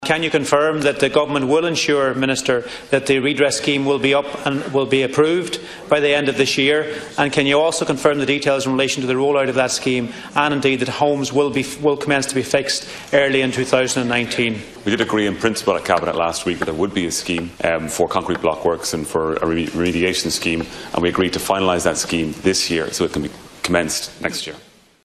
However more clarity surrounding the finer details of such a scheme was sought by Donegal Deputy Charlie McConalogue From Minister Eoghan Murphy in the Dail last night.